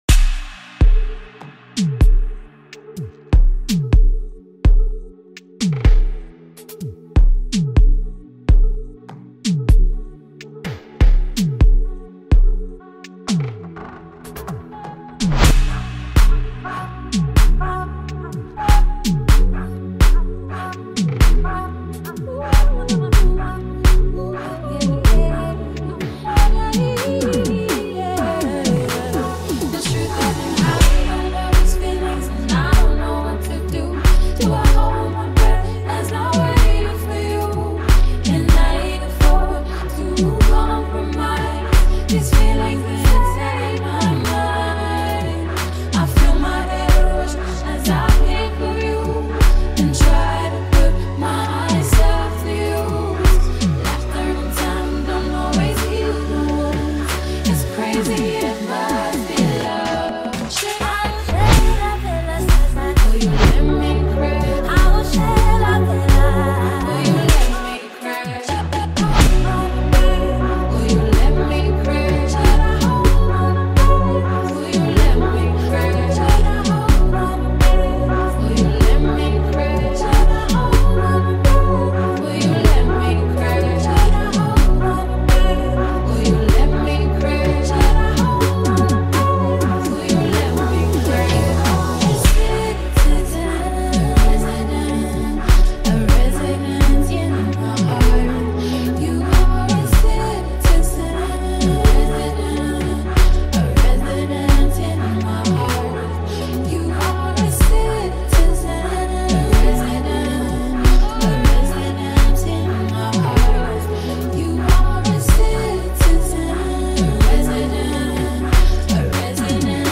powerful vocals